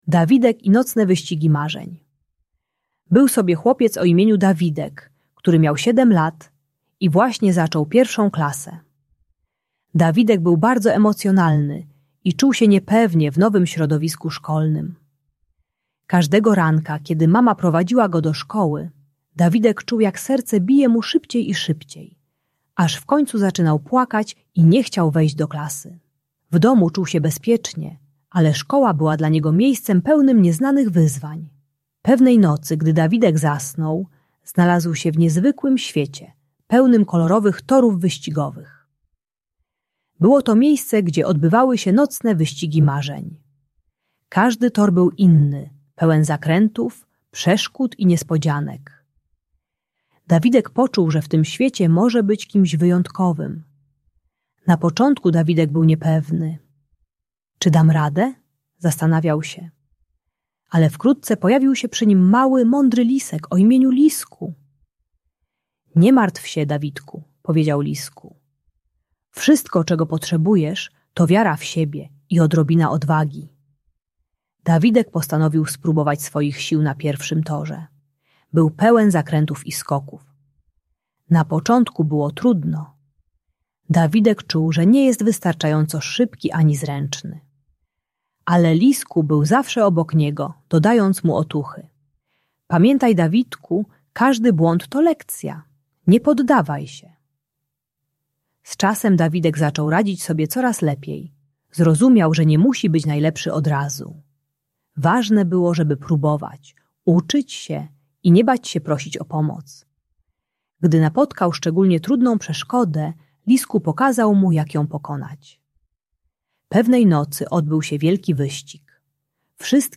Audiobajka o lęku przed szkołą uczy techniki budowania wiary w siebie poprzez małe kroki i proszenie o pomoc nauczyciela.